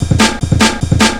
FILL 5    -R.wav